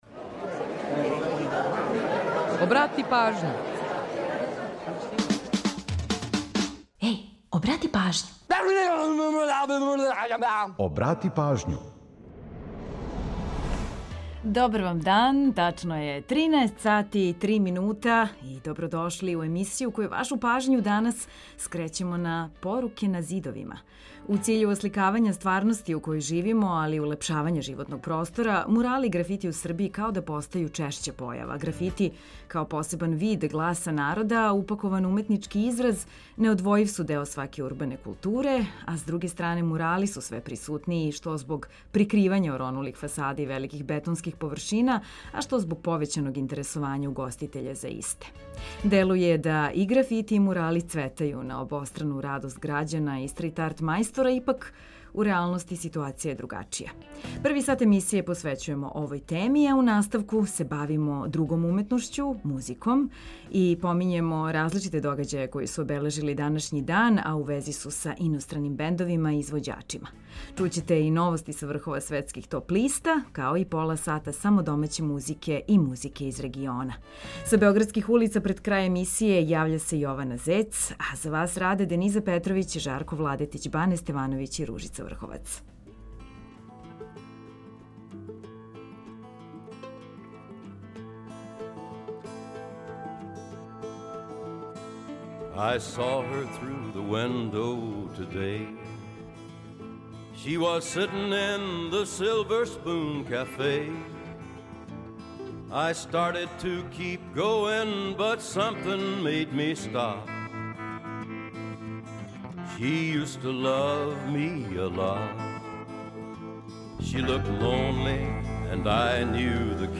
Помињемо различите догађаје који су обележили данашњи дан, а у вези су са иностраним бендовима и извођачима. Чућете и новости са врхова светских топ листа, као и пола сата само домаће и музике из региона.